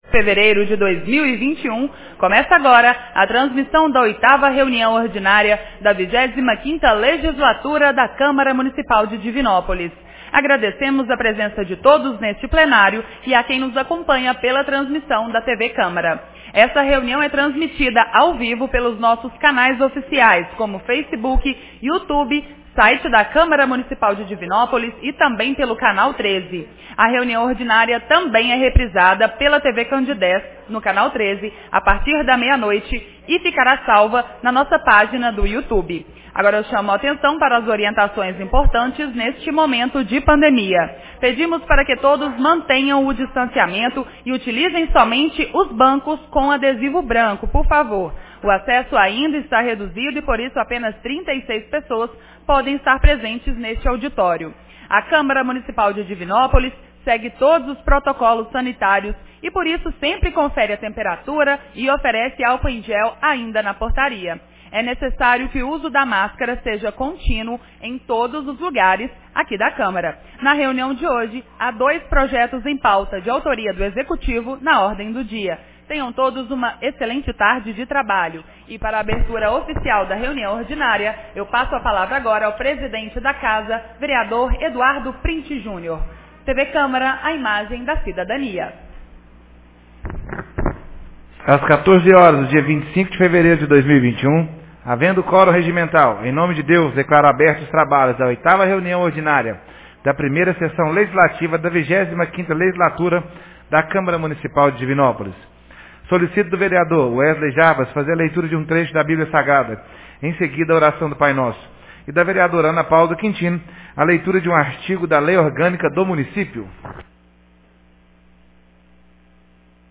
Reunião Ordinária 08 de 25 fevereiro 2021